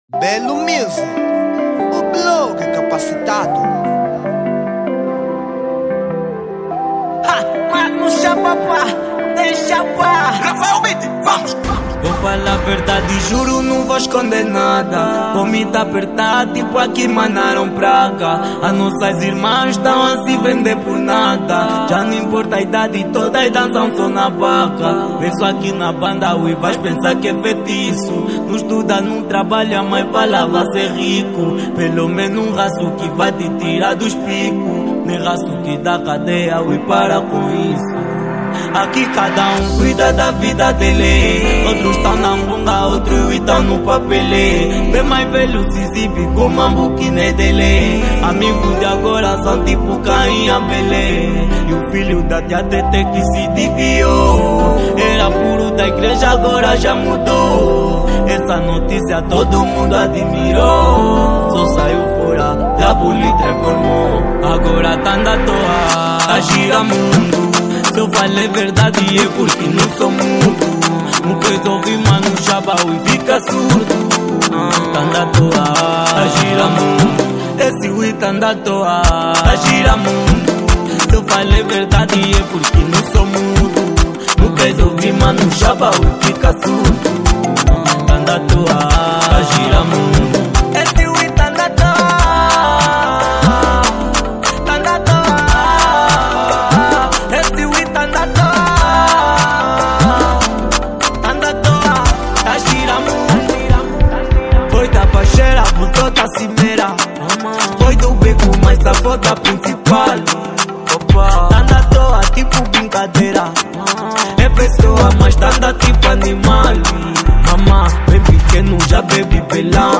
Género : Kuduro